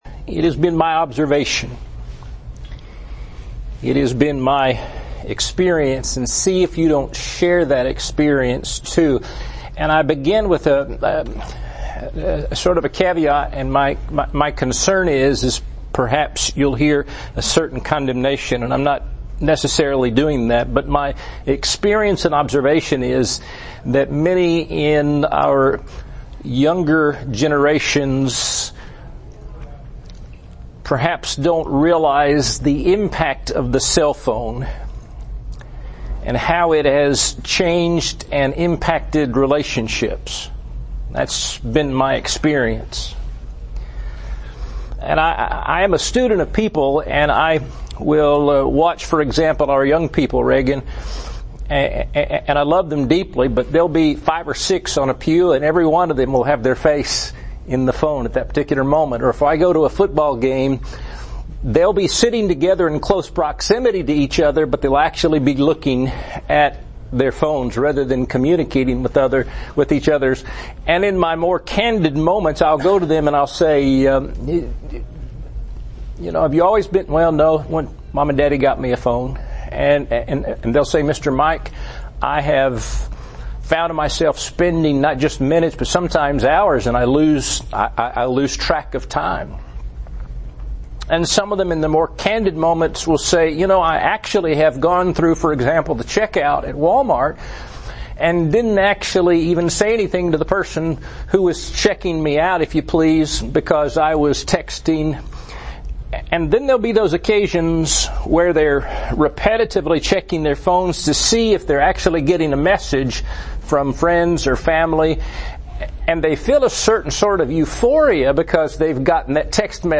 Audio lesson
A sermon for anxious hearts during the COVID-19 pandemic.